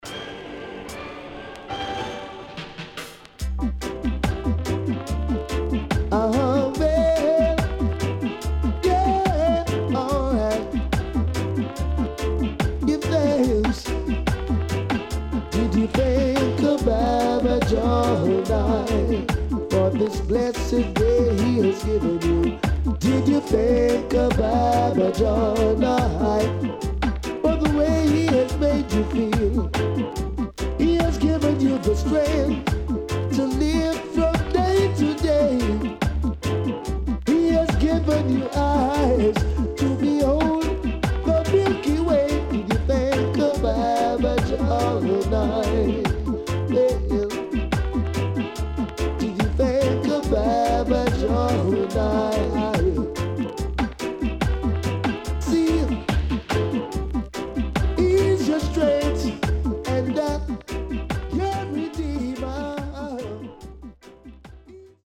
SIDE A:少しプチノイズ入りますが良好です。